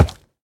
Minecraft Version Minecraft Version snapshot Latest Release | Latest Snapshot snapshot / assets / minecraft / sounds / mob / piglin / step3.ogg Compare With Compare With Latest Release | Latest Snapshot
step3.ogg